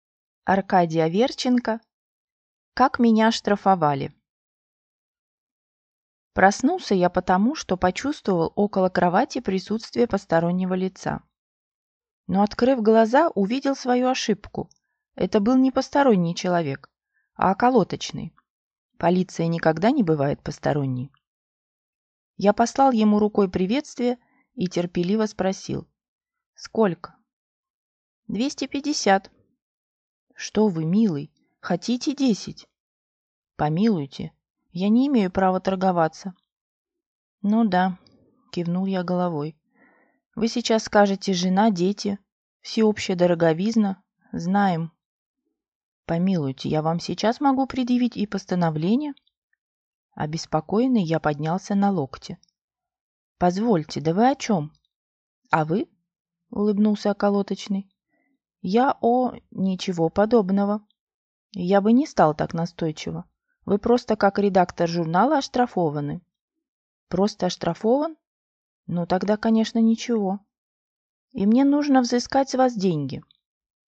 Aудиокнига Как меня оштрафовали